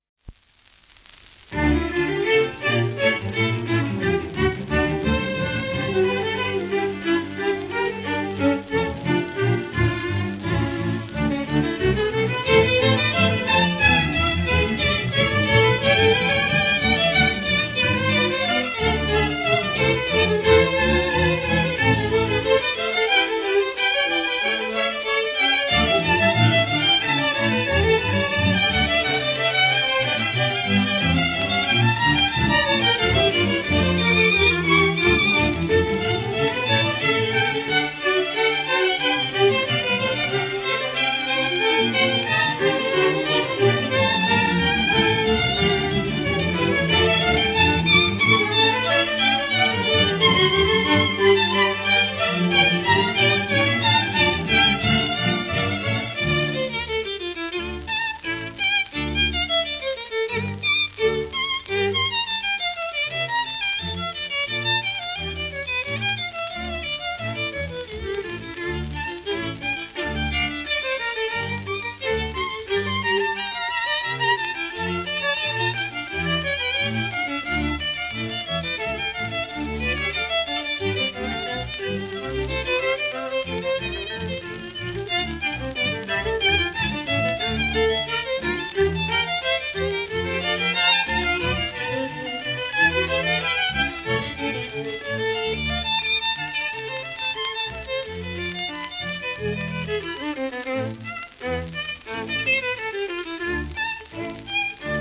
1932年パリ録音